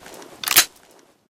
cloth.ogg